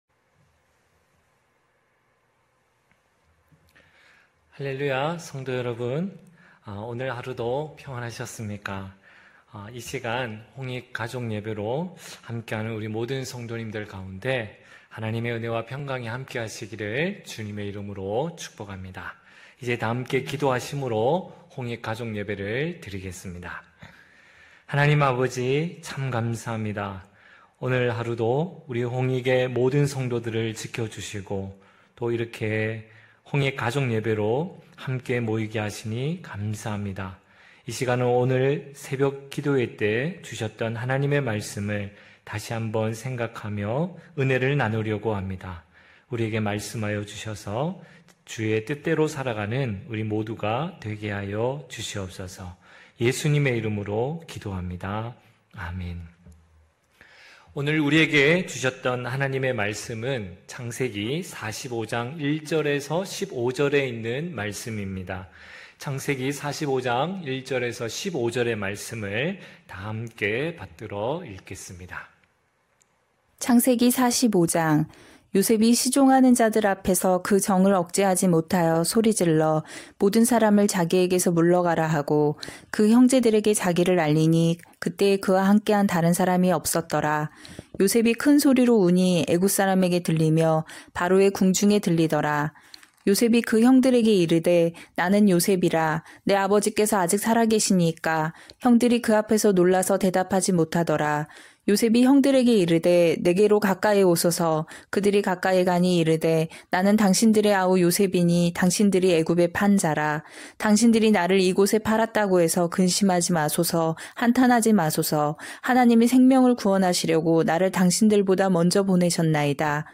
9시홍익가족예배(9월15일).mp3